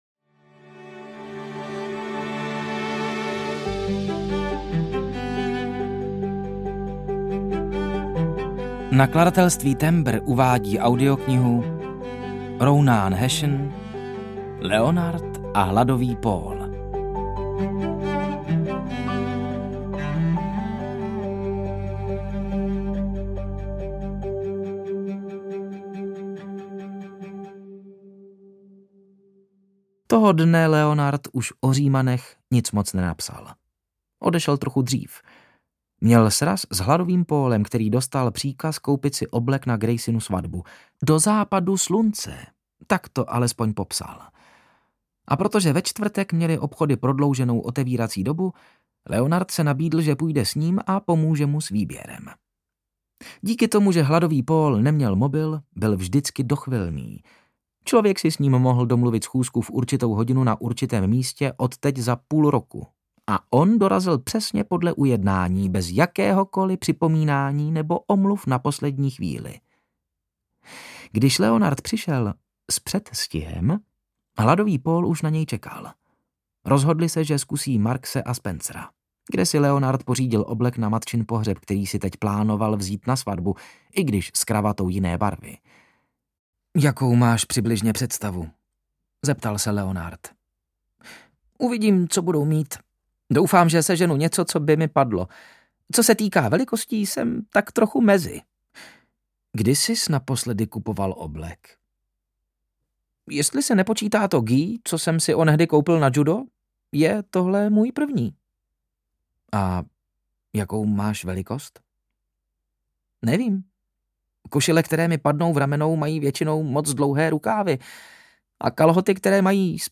Leonard a Hladový Paul audiokniha
Ukázka z knihy